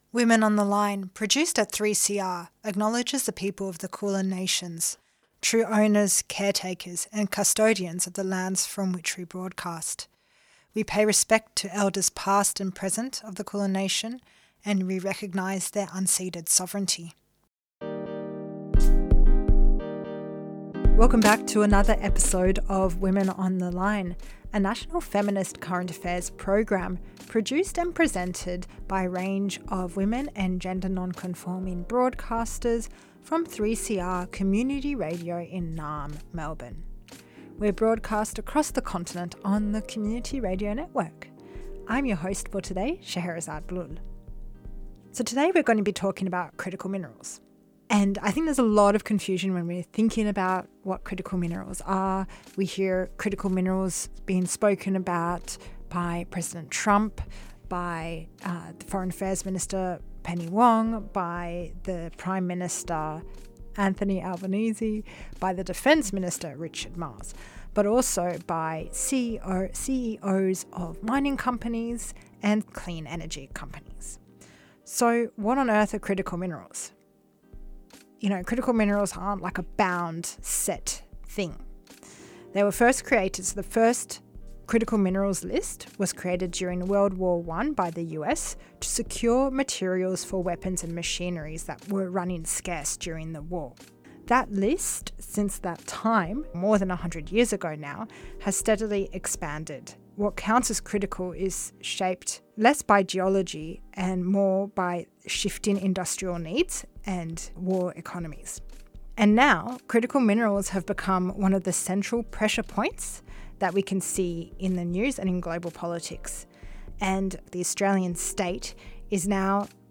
Tweet Women on the Line Monday 8:30am to 9:00am A national feminist current affairs program for community radio.